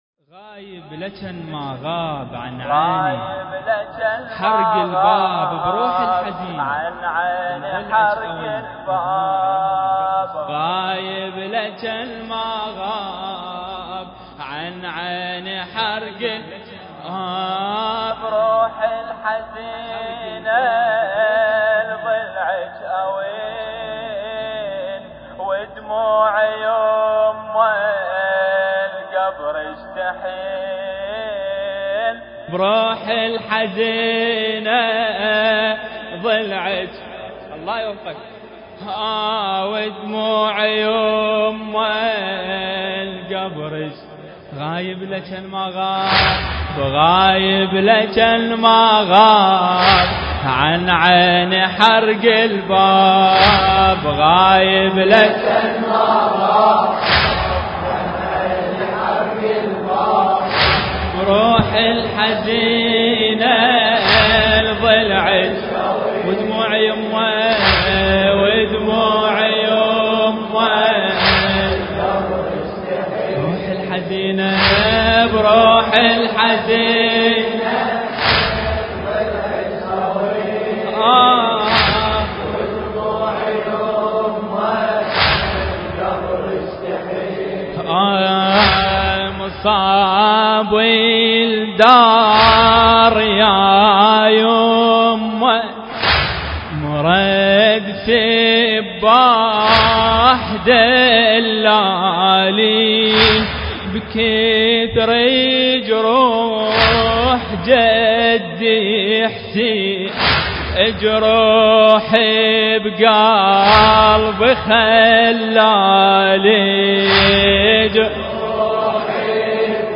المكان: هيئة أمير المؤمنين (عليه السلام) – كربلاء المقدسة
ذكرى شهادة السيدة فاطمة الزهراء (عليها السلام)